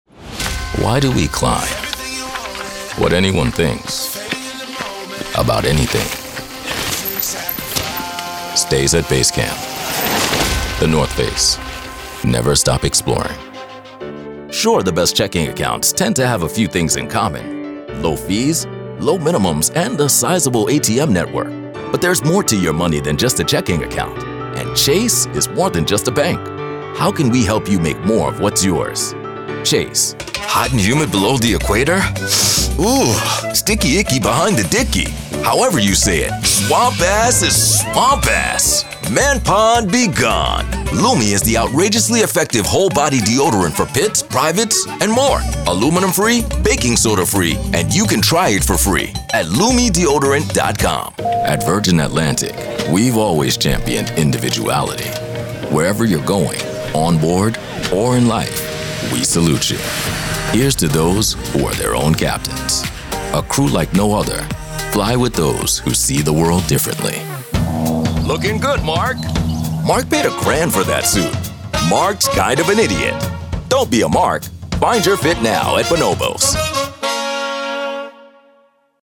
Profound, Resonant, Real.
Commercial